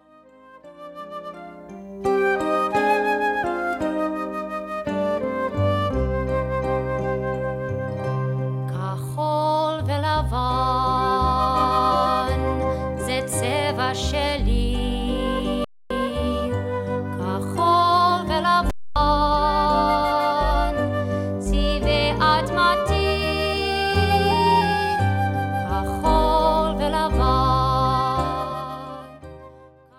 (Folk)